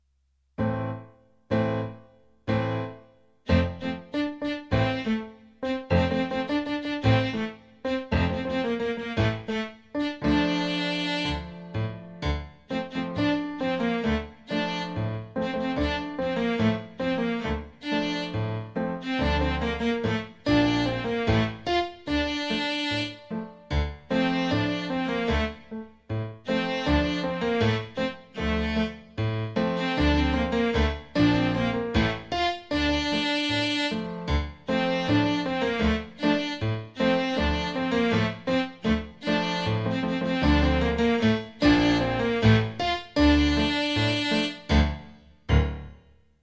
Just like in class, every recording will start with three piano chords to get ready before the melody starts and you can sing along (or simply follow along reading the words in the score).  I used a different “instrument” from my keyboard’s sound library for each melody.